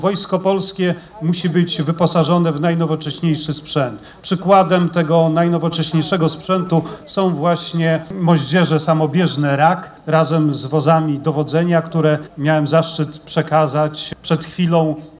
Jak powiedział minister Błaszczak, inwestycje w nowy sprzęt dla wojska, to priorytet obok zwiększenia liczby żołnierzy.